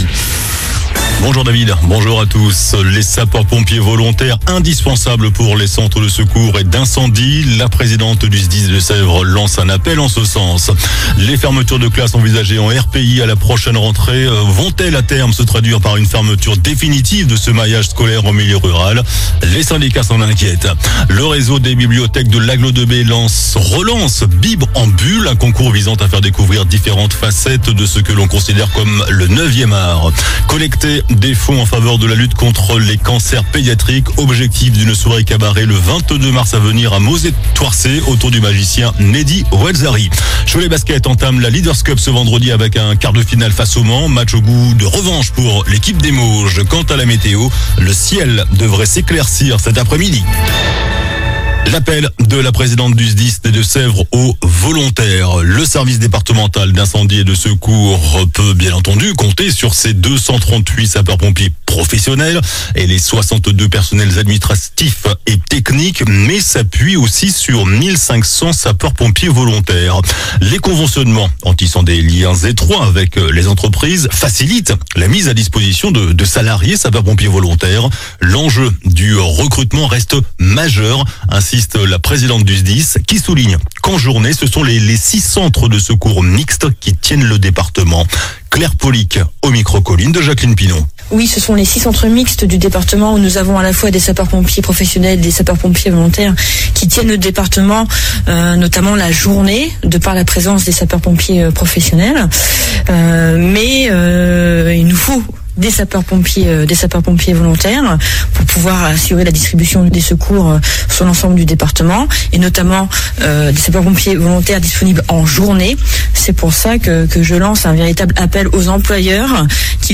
JOURNAL DU VENDREDI 14 FEVRIER ( MIDI )